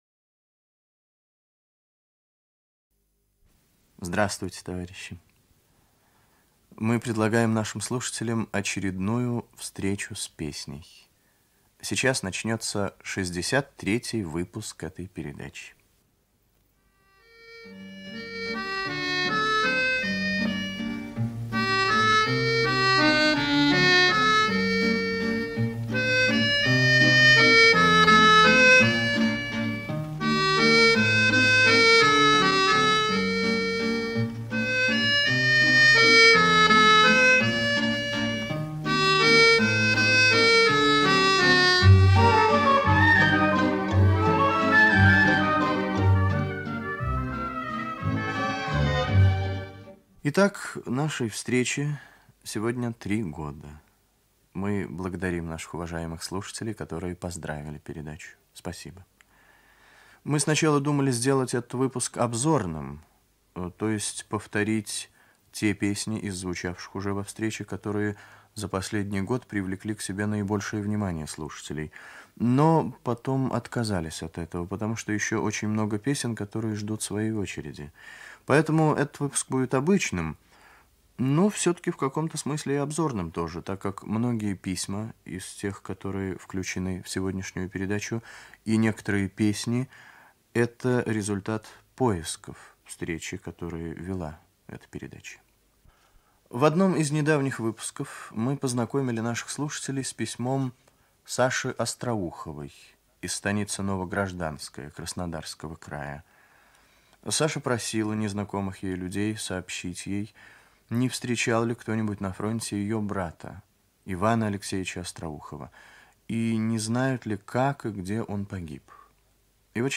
Ведущий - автор, Виктор Татарский.